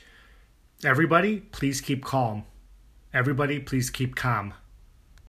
It’s the difference between “cah(l)m” and “caw(l)m.”
I dropped the “l” in the second pronunciation–not sure if you normally pronounce it with the “l” or not there (some people do; some people don’t). I can re-record with the “l” pronunciation, if you’d like, but you can get a sense of the difference of the quality of vowel there.
calm.m4a